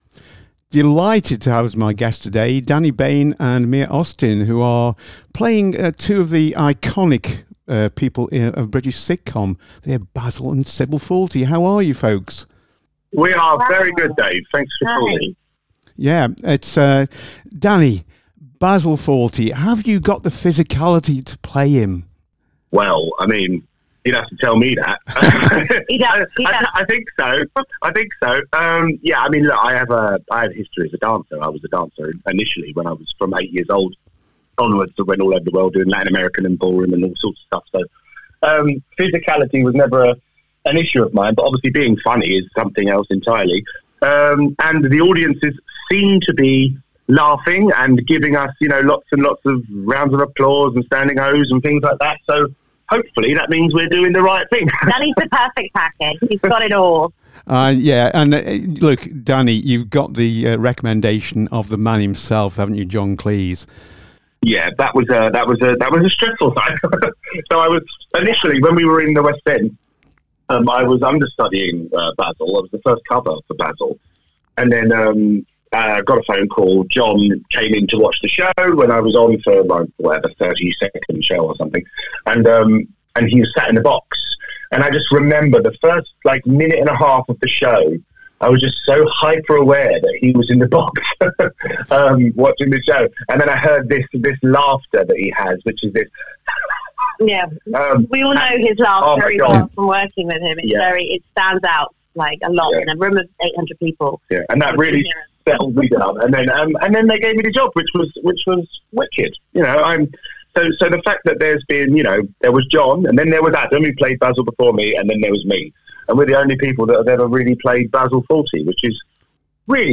in Conversation with Fawlty Towers - Moorlands Radio